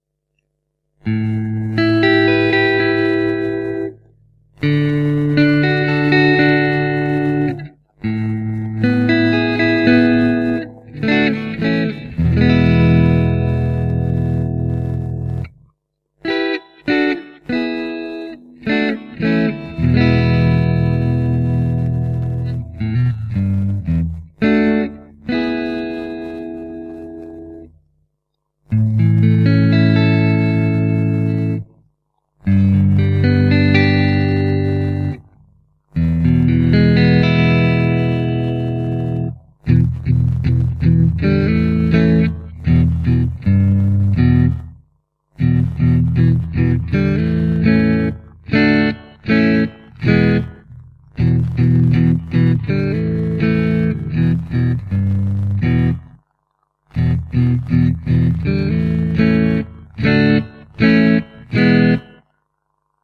The Dawn Angel offers sweet, transparent cleans with unrivalled clarity.
The highs are warm, the lows are firm but not aggressive, the mids are slightly muted and perfectly balanced.
Magnet Alnico 3